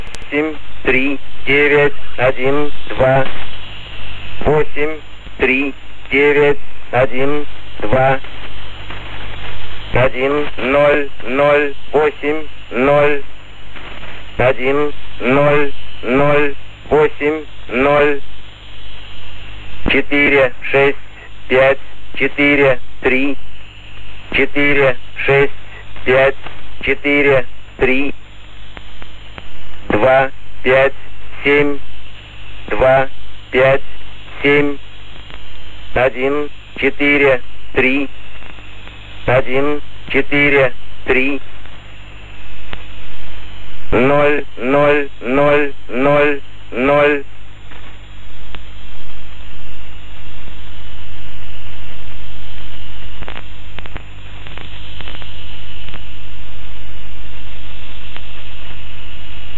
English: A recording of the "Russian Man" numbers station signing off. Recorded on 23 April 2013, 18:42 UTC on 13440 kHz from the Netherlands.